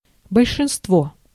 Ääntäminen
Ääntäminen France: IPA: /ply.paʁ/ Haettu sana löytyi näillä lähdekielillä: ranska Käännös Ääninäyte Substantiivit 1. большинство {n} (bolšinstvo) Suku: f .